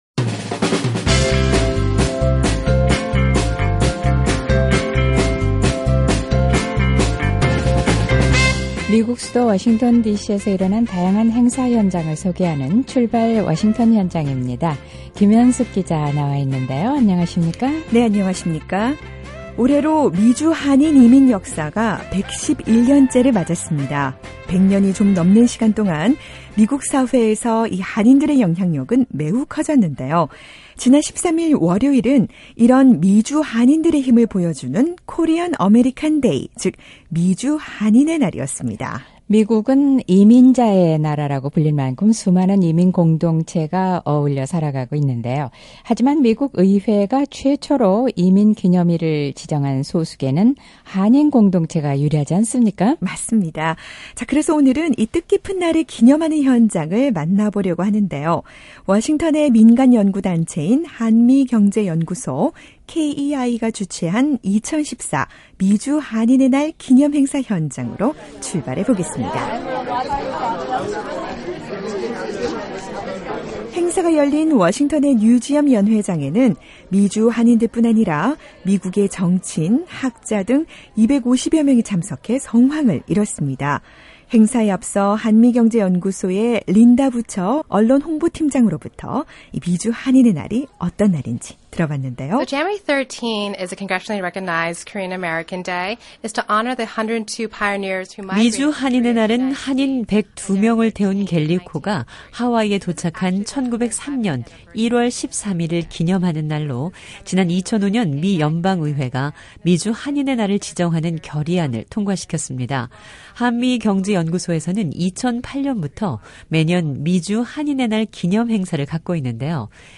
미주 한인 이민 111 주년을 맞은 1월 13일, 워싱턴의 민간단체 한미경제연구소는 ‘미주 한인의 날 기념행사’를 갖고 미국 사회에서 한인들이 이룬 다양한 업적을 기념하고 축하했습니다. 올해는 특히 사업가 세 명이 자랑스러운 한인상 수상자로 선정됐는데요. 소수계라는 약점을 극복하고 어떻게 성공 신화를 써내려 가고 있는지 현장을 찾아 확인해 보시죠.